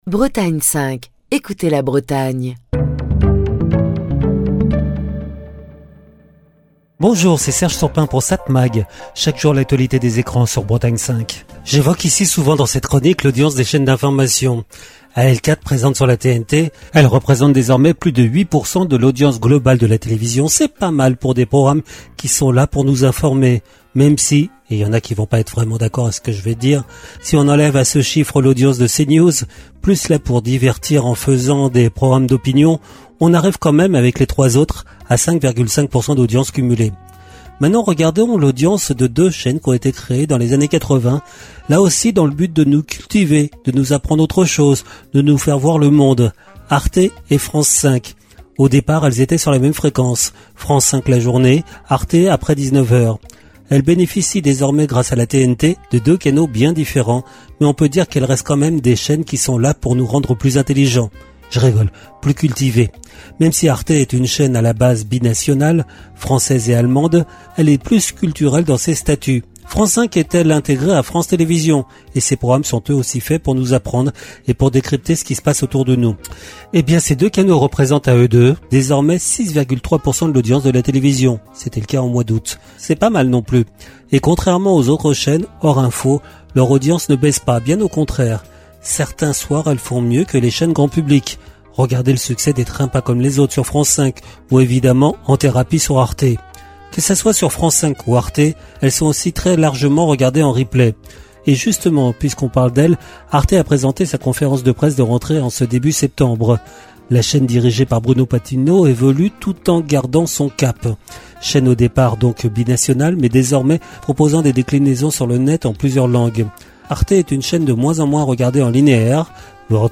Chronique du 27 mars 2025.